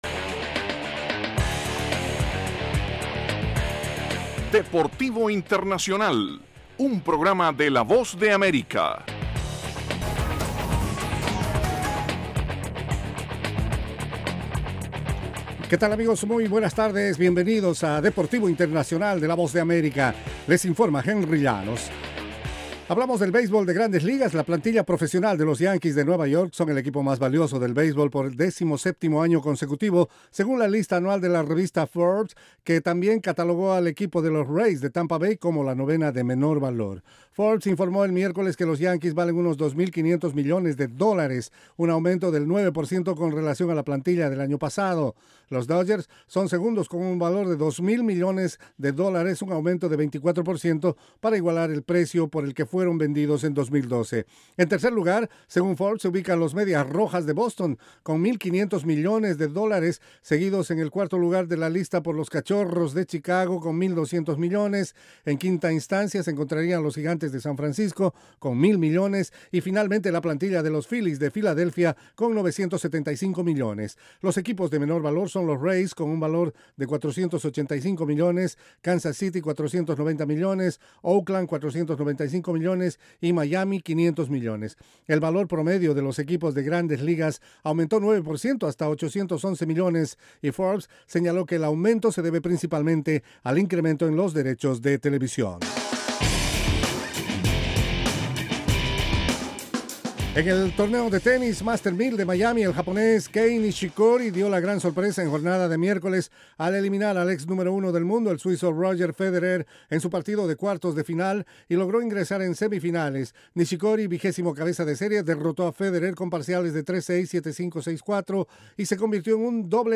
presenta las noticias más relevantes del mundo deportivo desde los estudios de la Voz de América. Deportivo Internacional se emite de lunes a viernes, de 12:05 a 12:10 de la tarde (hora de Washington).